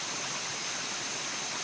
spray.ogg